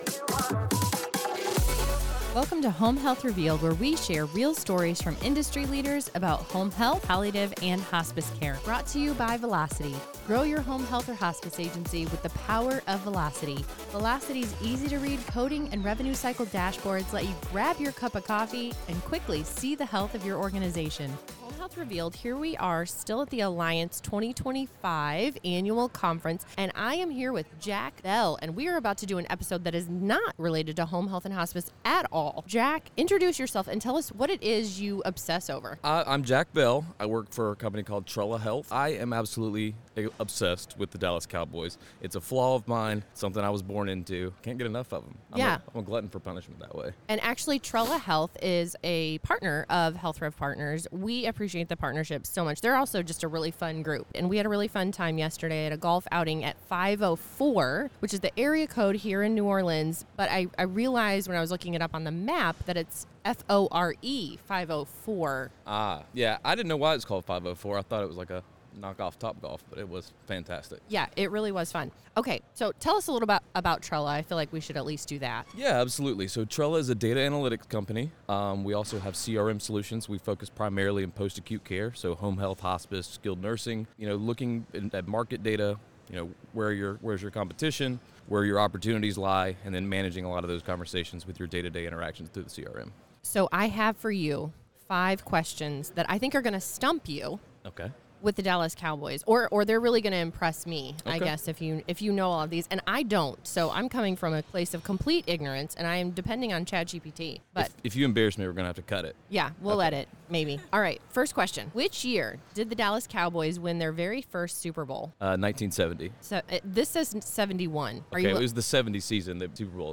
Sometimes you have to call an audible! It's a light-hearted break full of laughs so whether you're a football fan or just here to enjoy a little chaos between conference sessions, tune in!